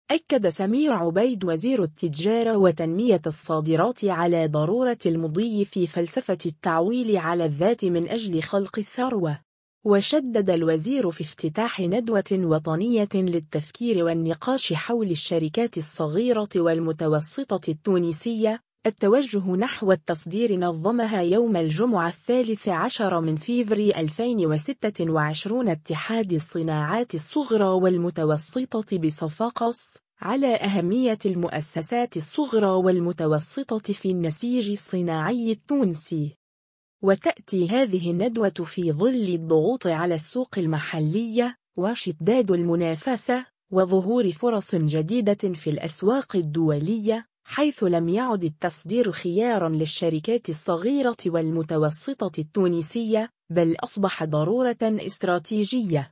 في صفاقس: وزير التجارة يفتتح ندوة حول “الشركات الصغيرة والمتوسطة : التوجه نحو التصدير” (فيديو)
وشدد الوزير في افتتاح ندوة وطنية للتفكير والنقاش حول “الشركات الصغيرة والمتوسطة التونسية: التوجه نحو التصدير” نظمها يوم الجمعة 13 فيفري 2026 اتحاد الصناعات الصغرى والمتوسطة بصفاقس، على أهمية المؤسسات الصغرى والمتوسطة في النسيج الصناعي التونسي.